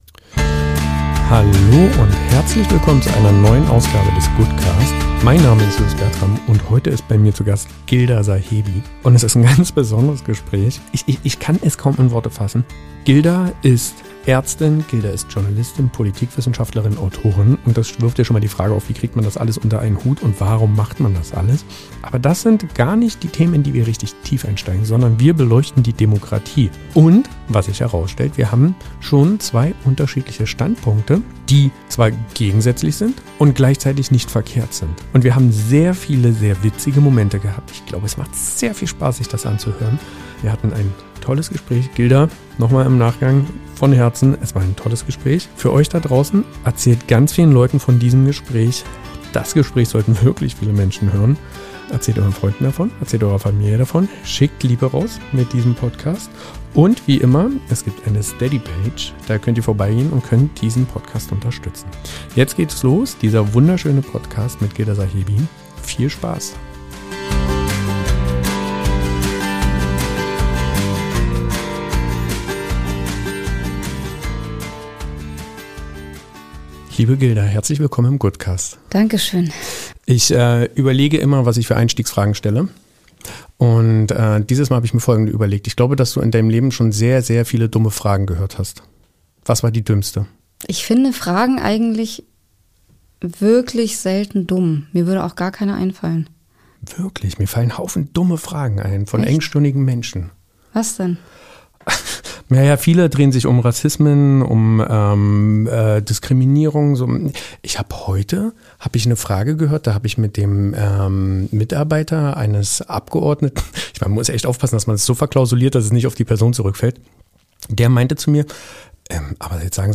In dieser Goodcast-Episode hören wir eine Stimme, die durch Leidenschaft, Klarheit und Empathie besticht.